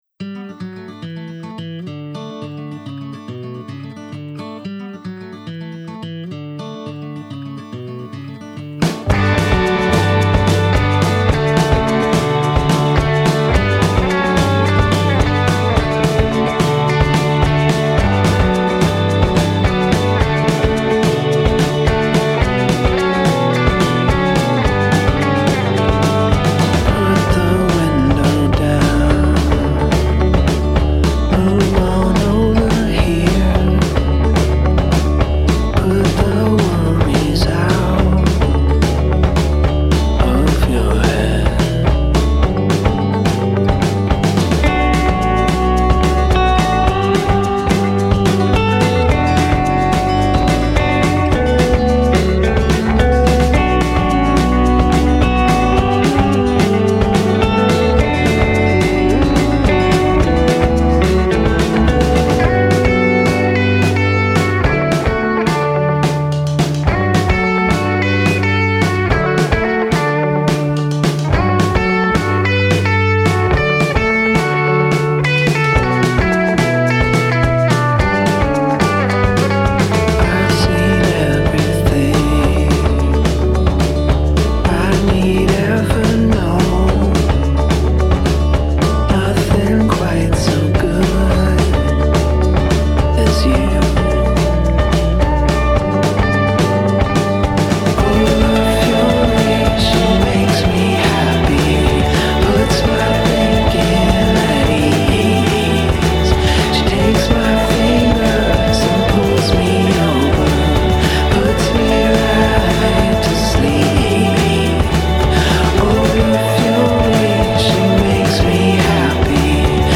mid-’90s British rock
lead vocal, guitar, keyboard
drums
bass, vocals